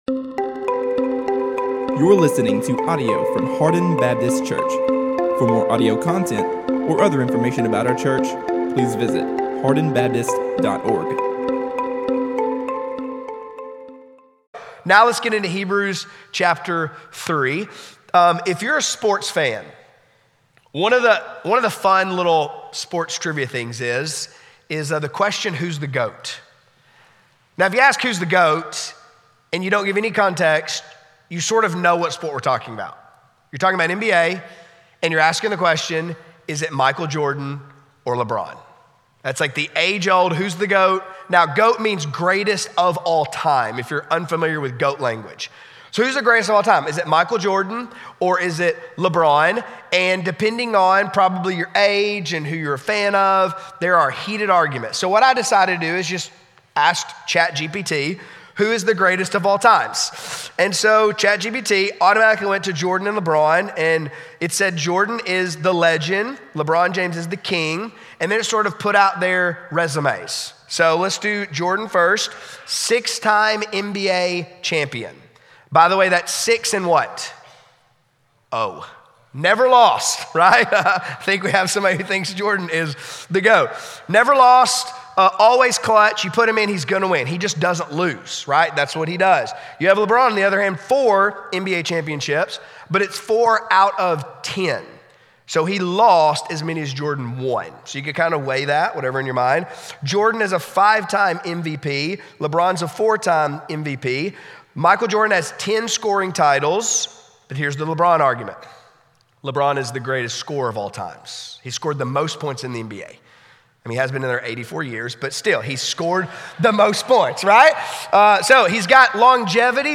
A message from the series "Youth."